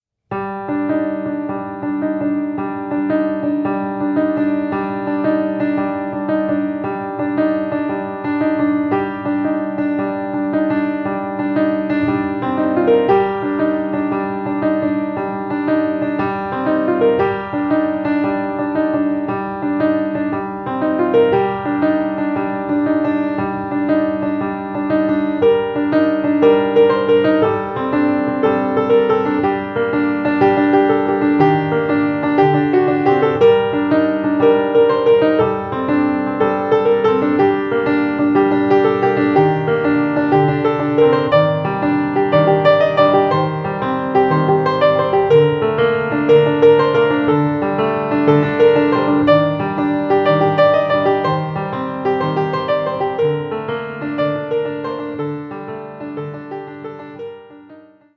piano (Blüthner Model 4)